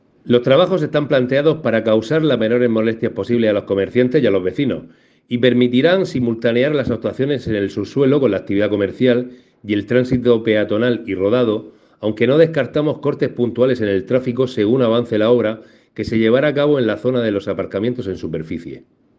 Enlace a Declaraciones de Diego Ortega